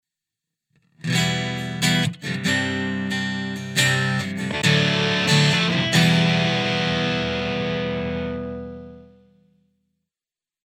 Nun fällt mir auf, dass ich beim Snapshotwechsel ein "knacken" oder eher ein Mini-Delay drin habe, das sehr stört. Ich hab mir aus dem Placater Dirty auf einem Snapshot einen "cleanen" und auf nem anderen einen "crunchigen" Sound eingestellt.